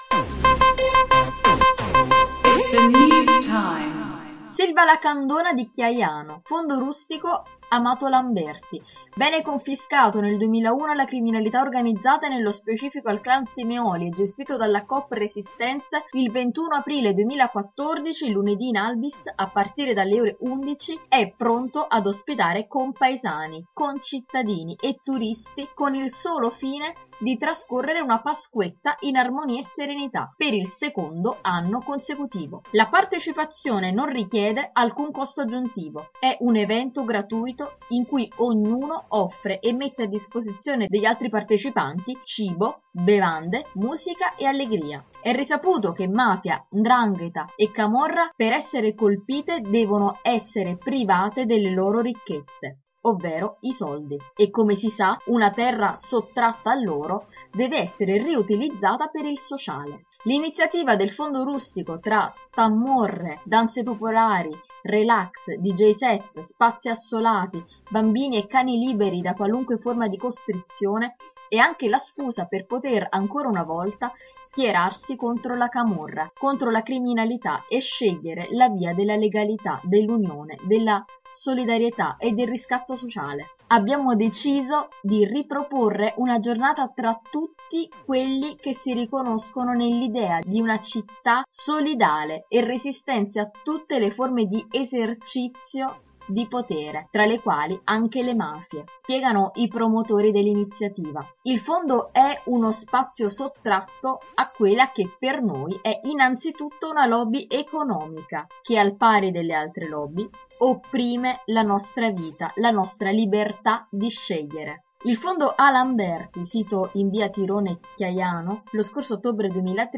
Se vuoi ascoltare l’articolo letto dalle nostre redattrici clicca qui